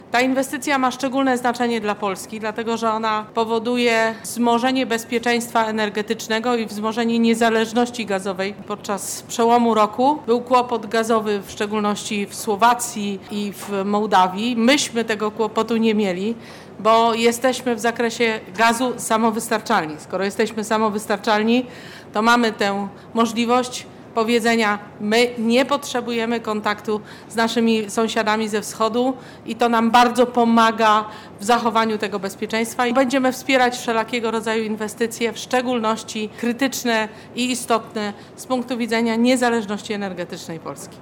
Minister Przemysłu Marzena Czarnecka, obecna podczas uroczystości zakończenia rozbudowy Terminala podkreśliła, że inwestycja ma strategiczne znaczenie dla Polski